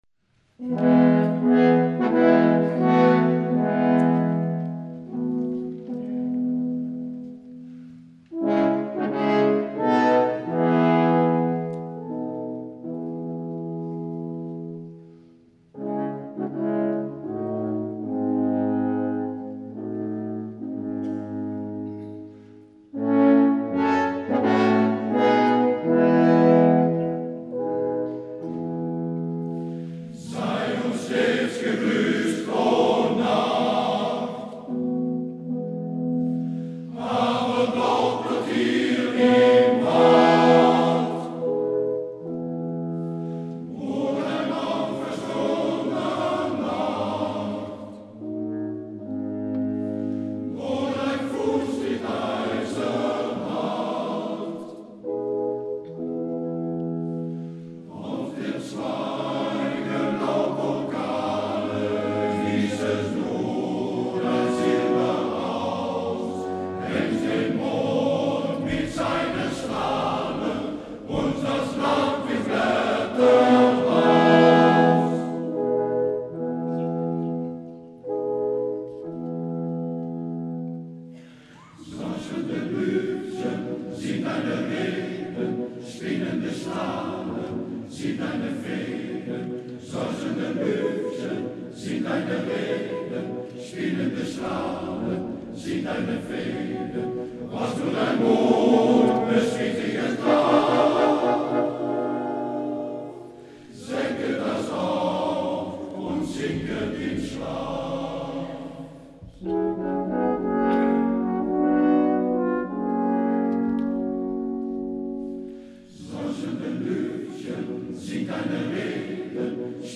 Hieronder treft u een aantal muziekfragmenten aan van Mannenkoor Lambardi.